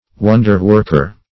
Wonder-worker \Won"der-work`er\, n. One who performs wonders, or miracles.